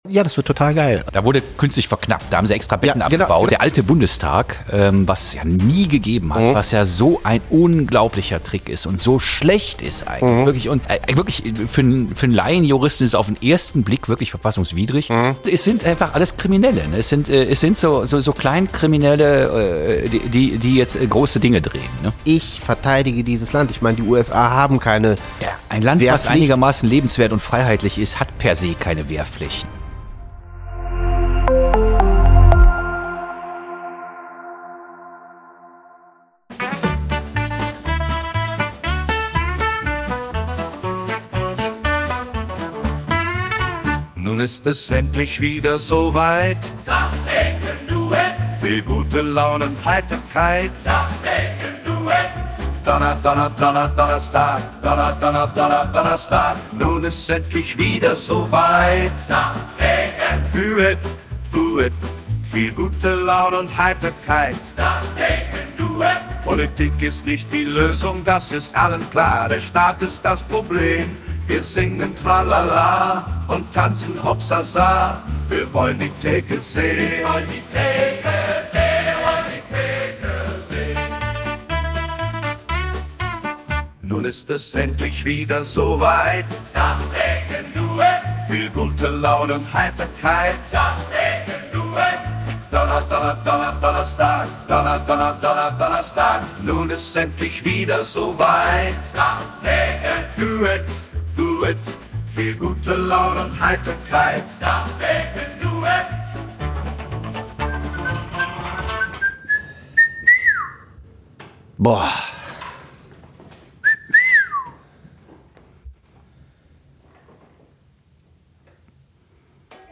Kleine Redaktionskonferenz am Tresen